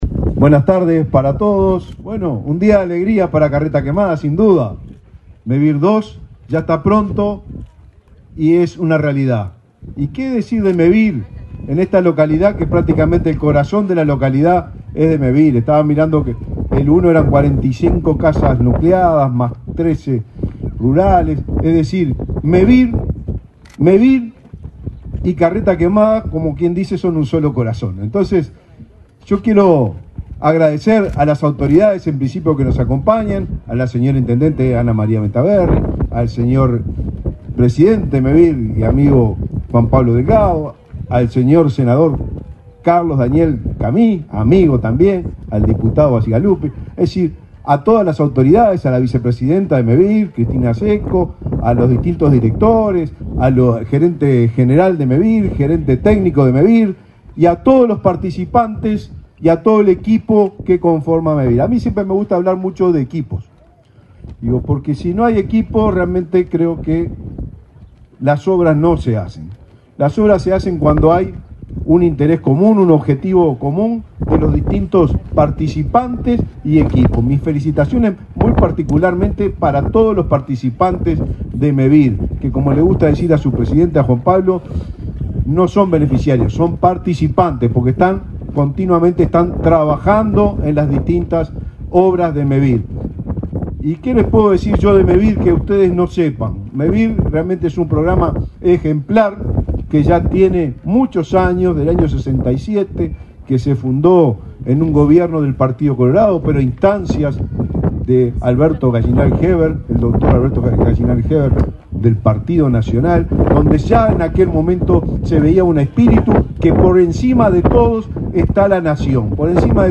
Palabras del ministro de Vivienda y Ordenamiento Territorial, Raúl Lozano
Palabras del ministro de Vivienda y Ordenamiento Territorial, Raúl Lozano 08/10/2024 Compartir Facebook X Copiar enlace WhatsApp LinkedIn En la ceremonia de inauguración de un plan de viviendas de Mevir en Carreta Quemada, este 8 de octubre, se expresó el ministro de Vivienda y Ordenamiento Territorial, Raúl Lozano.